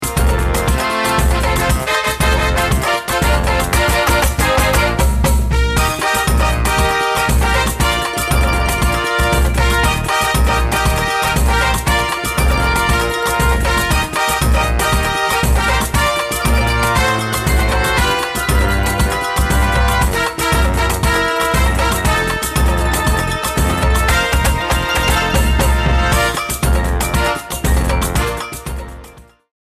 section of the music heard during the trophy ceremony